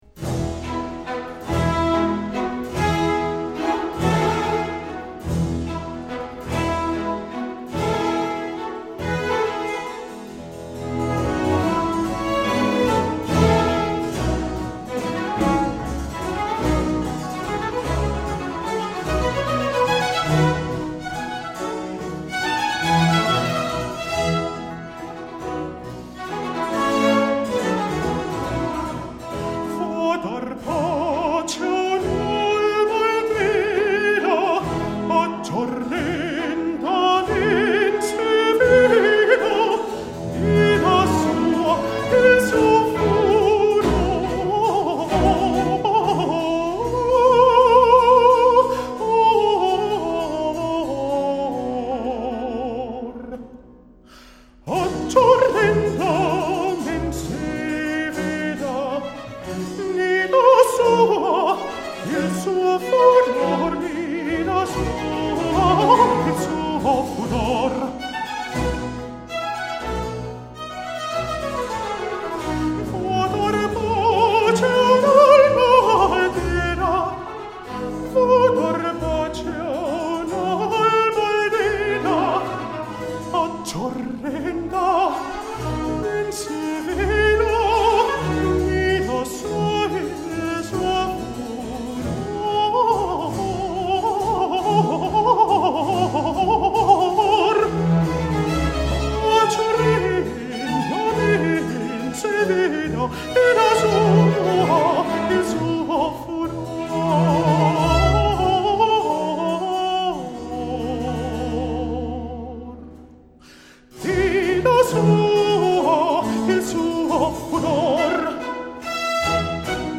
Aria